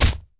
outlaw_hit.wav